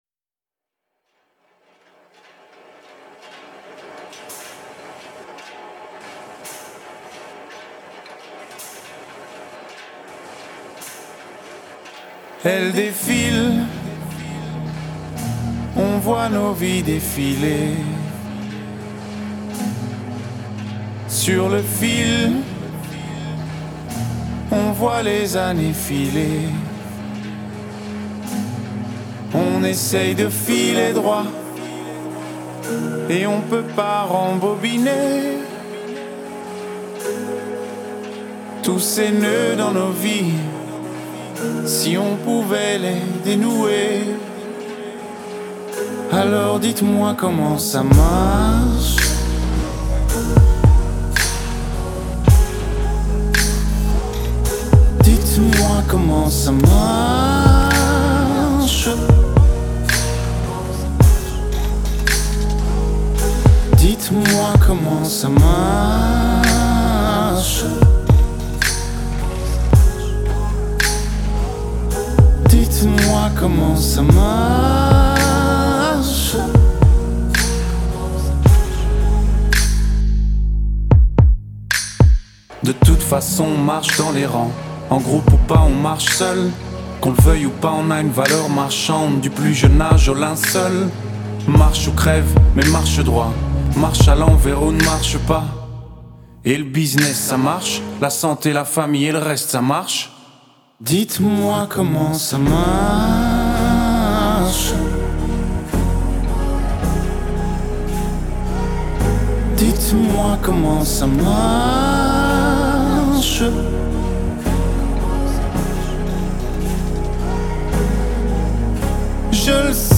элементы поп, электронной музыки и хип-хопа